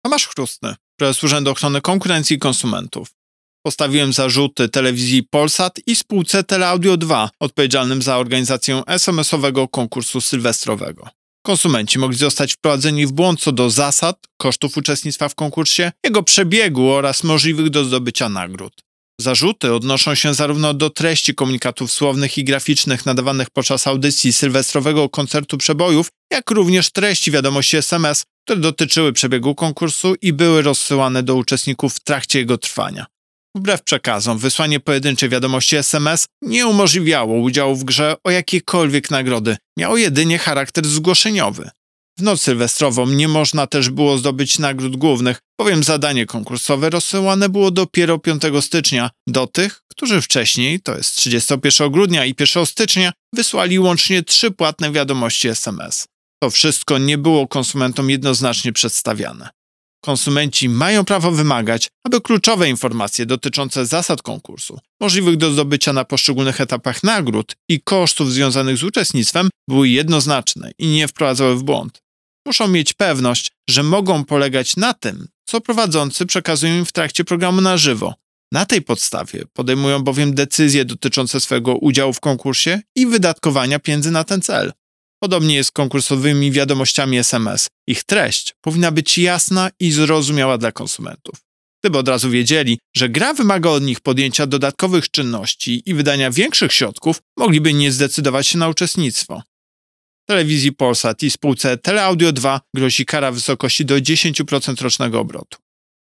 Pobierz wypowiedź Prezesa UOKiK Tomasza Chróstnego Wbrew przekazom wysłanie pojedynczej wiadomości SMS (koszt 3,69 zł) nie umożliwiało udziału w grze o przewidziane nagrody, miało jedynie charakter zgłoszeniowy.